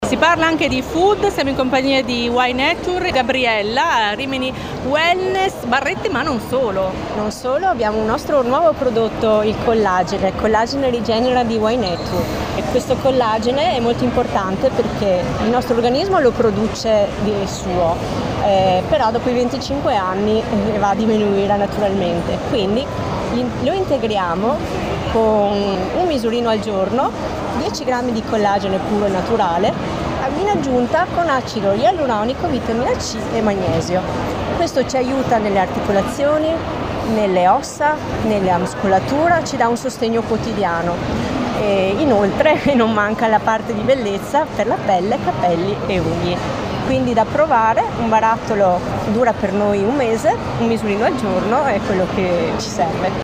RIMINI WELNESS - Radio International Live